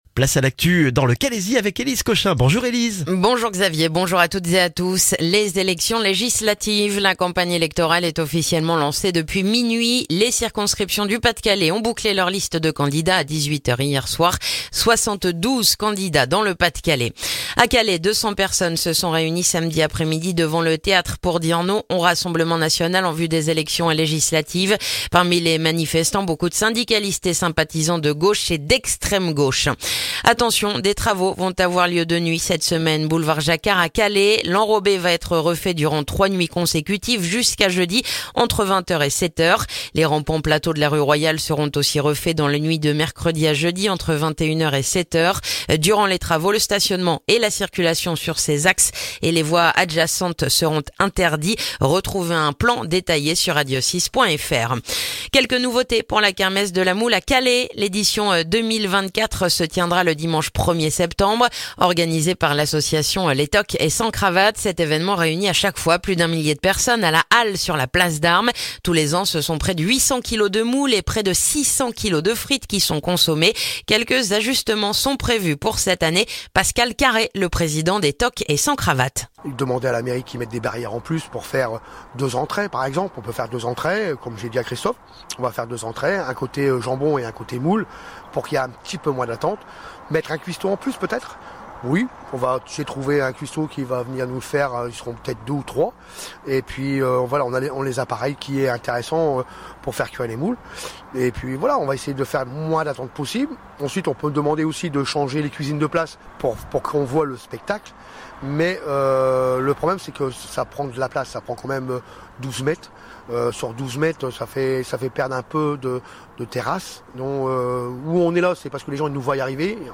Le journal du lundi 17 juin dans le calaisis